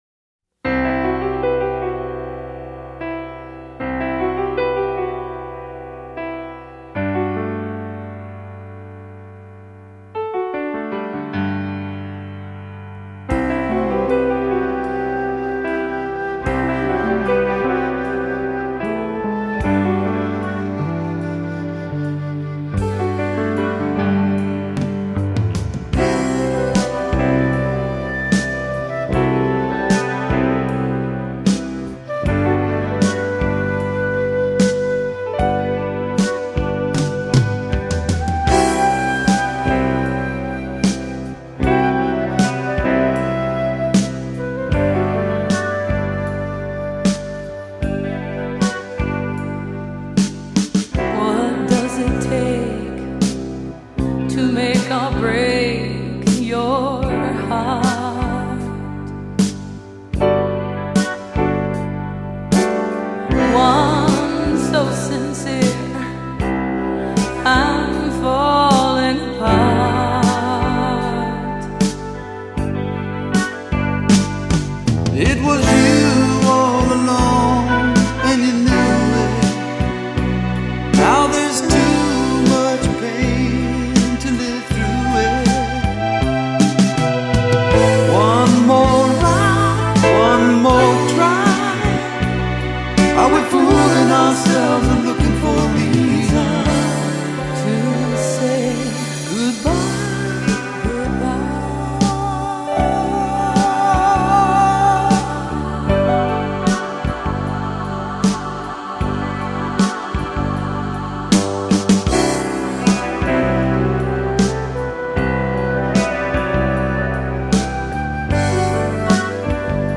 vocals, guitar
Vocals, flute
Guitar, keyboards